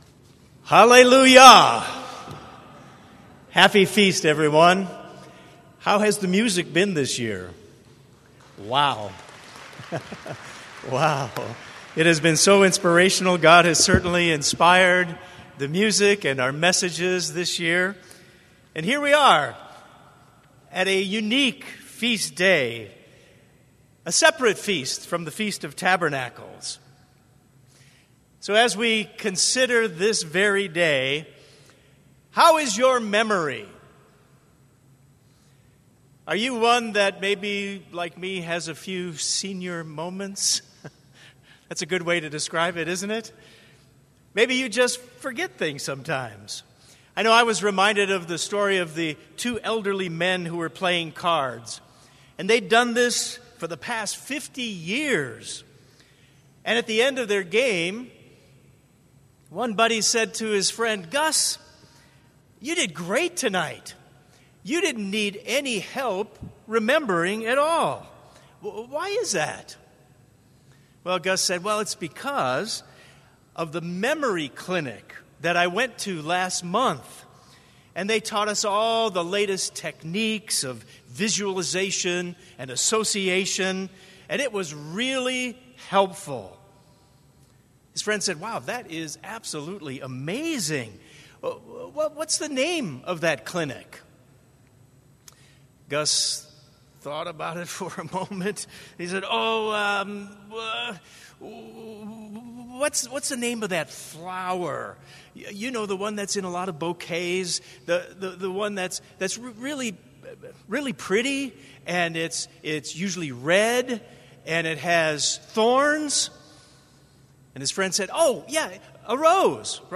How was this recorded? This sermon was given at the St. George, Utah 2020 Feast site.